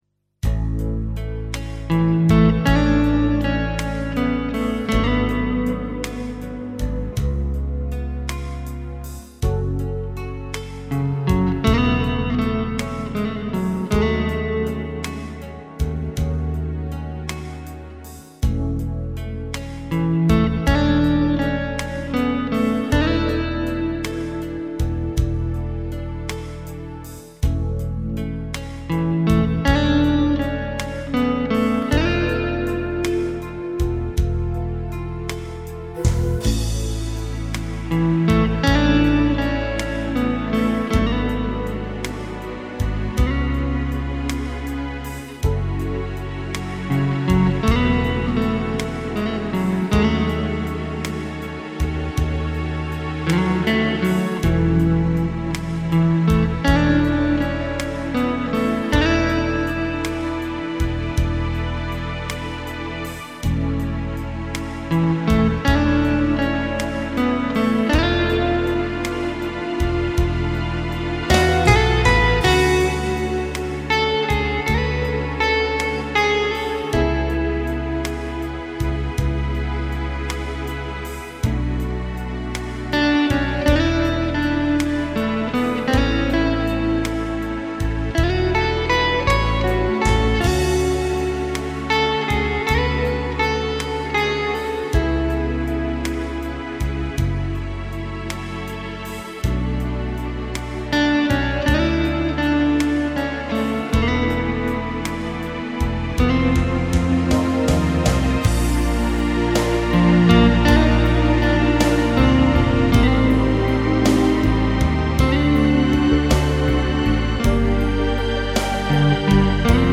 Luogo esecuzioneReggio Emilia
GenereWorld Music / New Age